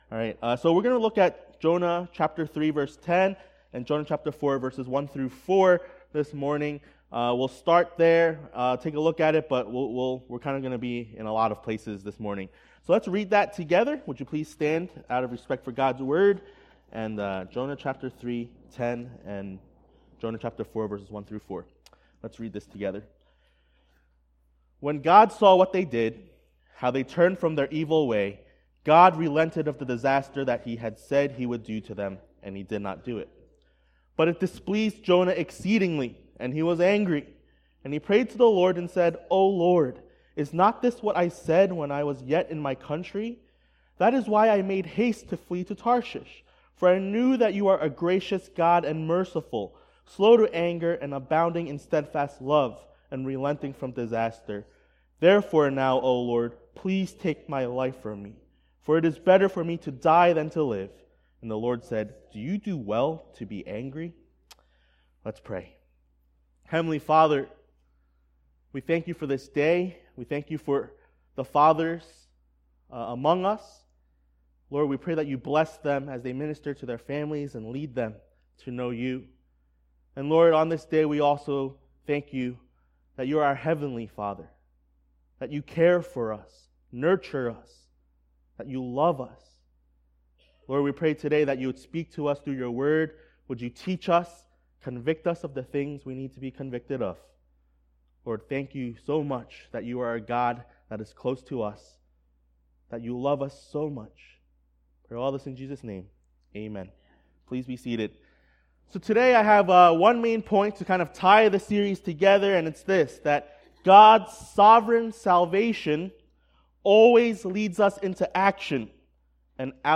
A message from the series "Jonah: Faith in Action ."